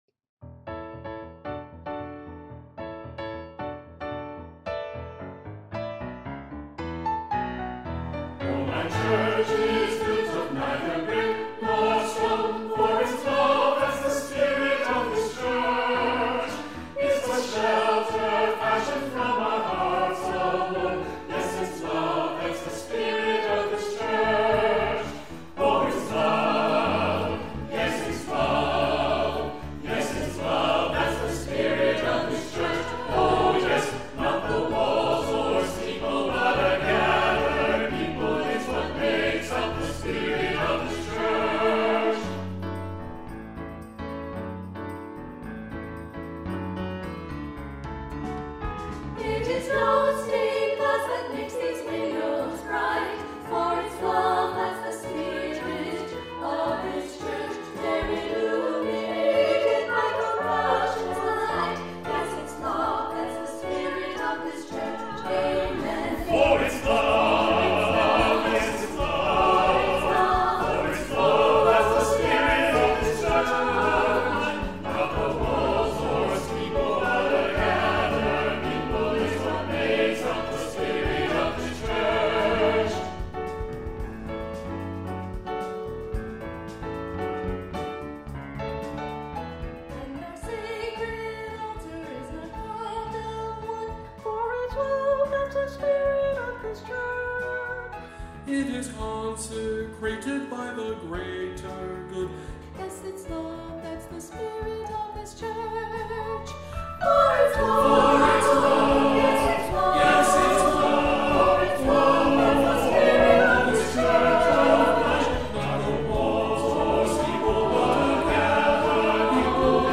A feast of old-time gospel, with a vision for our time.
SATB, piano